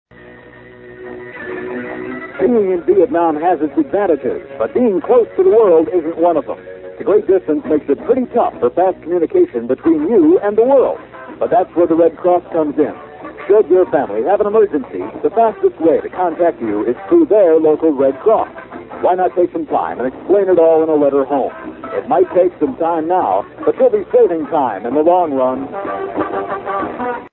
Red Cross PSA